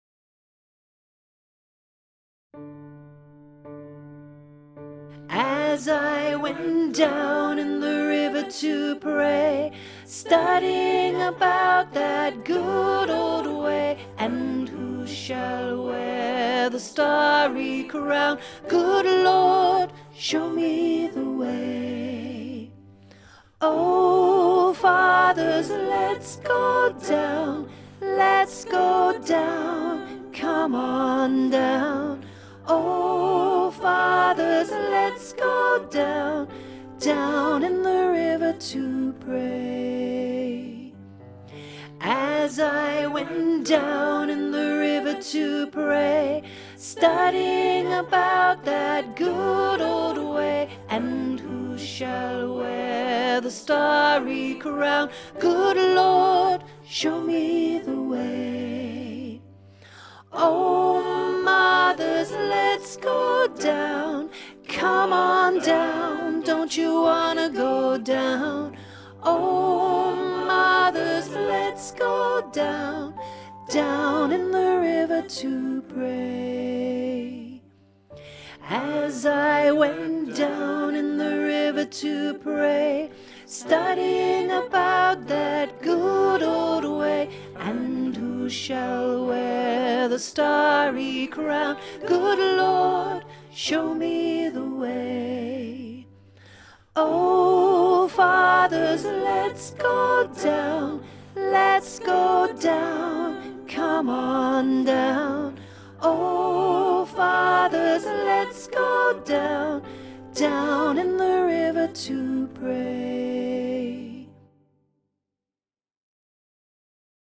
34 Down in the River to Pray (Alto learning track)
Genre: Choral.